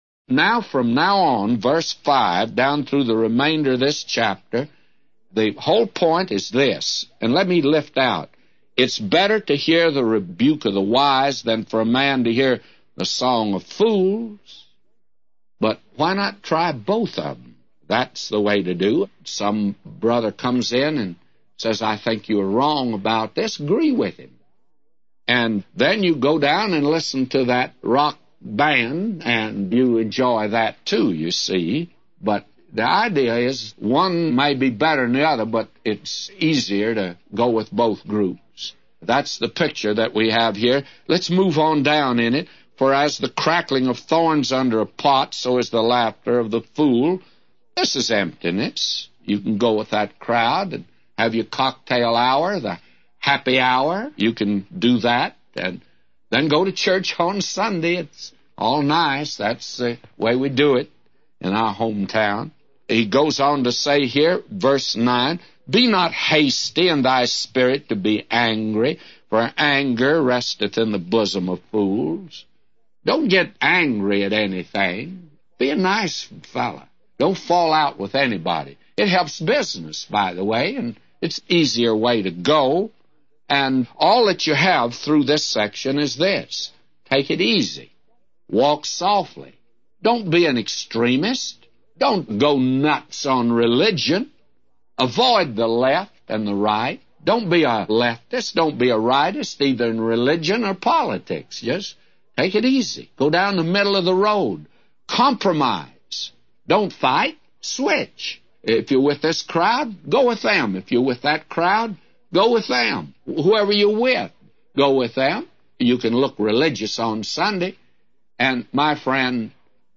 A Commentary By J Vernon MCgee For Ecclesiastes 7:5-999